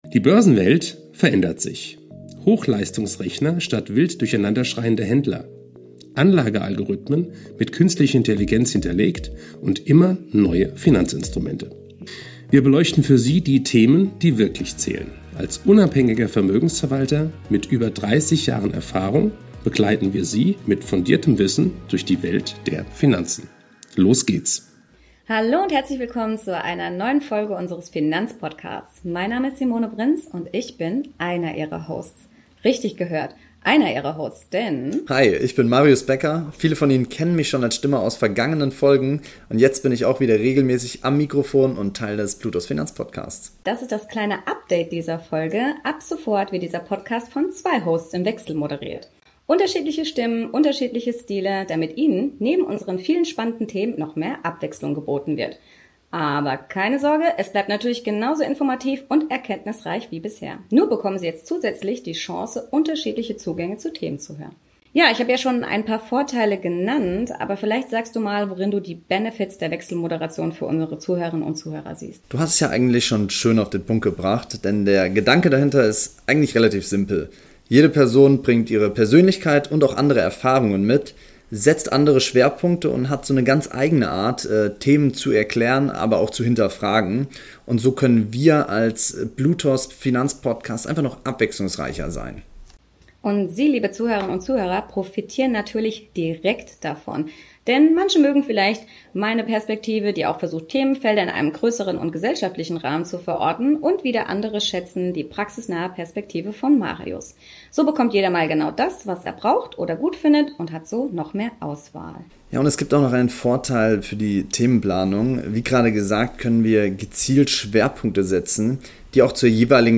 Unser Finanzpodcast startet in einem neuen Format – mit
gesagt: Zwei Stimmen, ein Ziel – dein Investmentwissen auf das